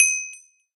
successful_hit.ogg